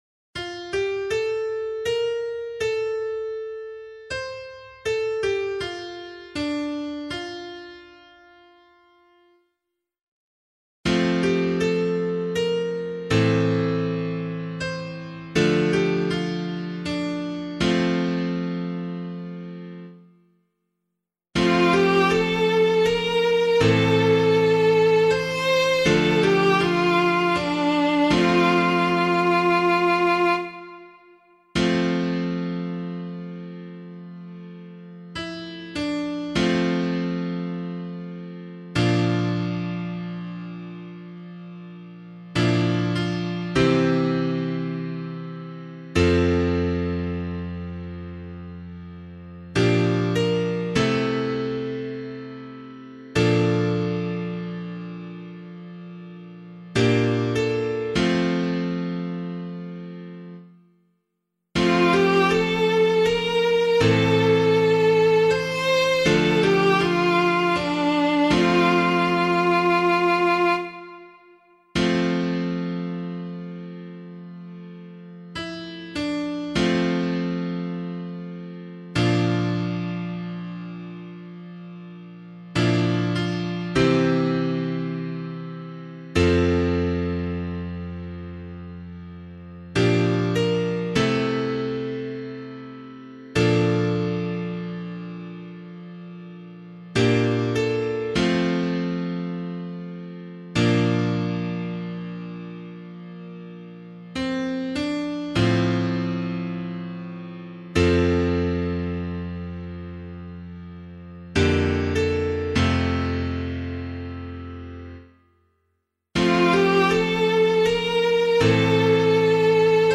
pianovocal